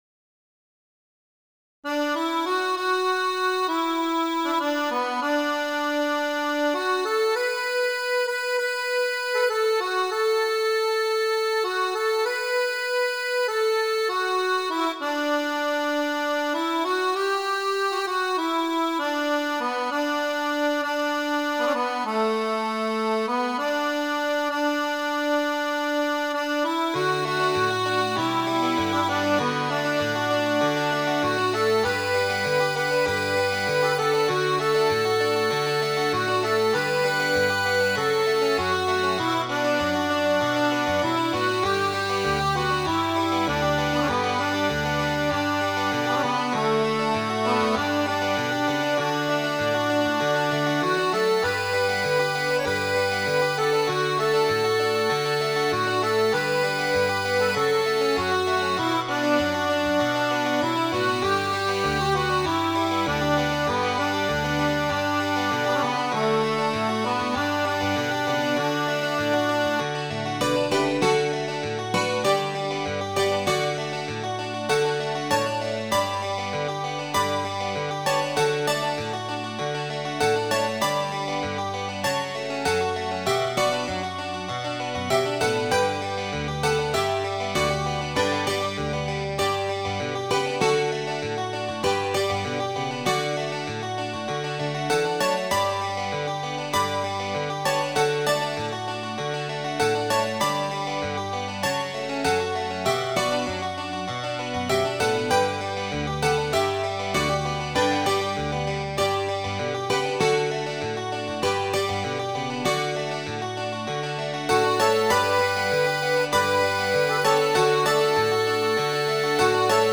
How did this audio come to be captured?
willyego.mid.ogg